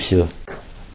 Звук, в котором мужчина произносит Всё